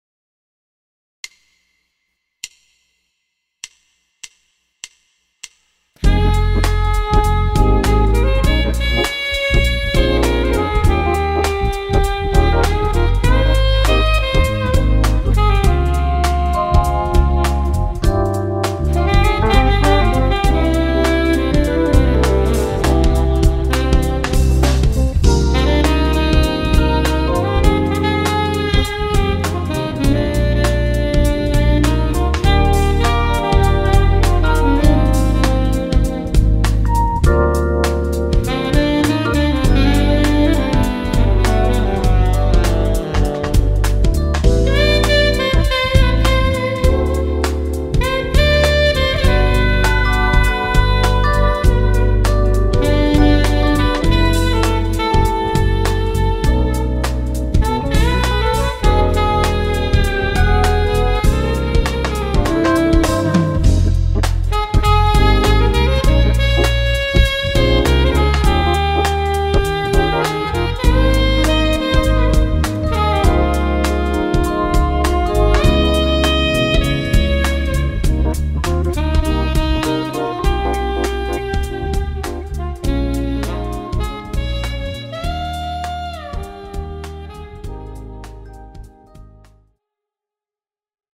SAX & VOICE
Lounge – Smoothjazz: